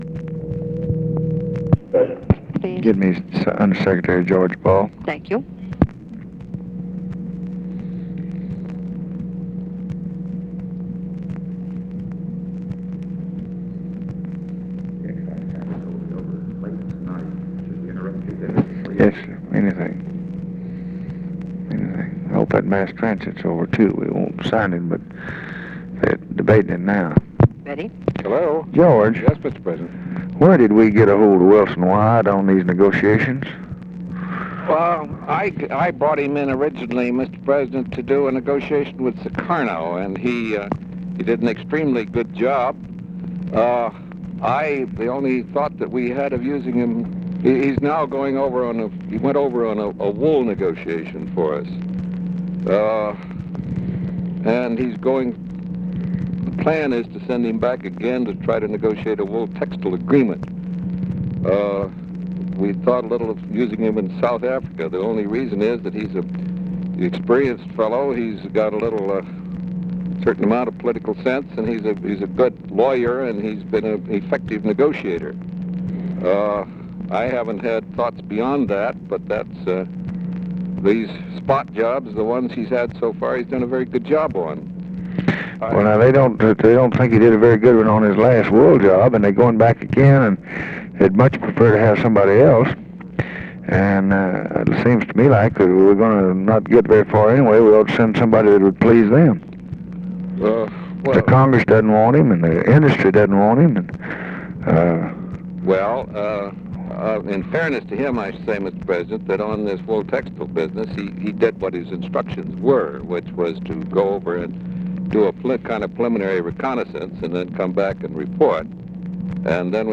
Conversation with GEORGE BALL and OFFICE CONVERSATION, June 30, 1964
Secret White House Tapes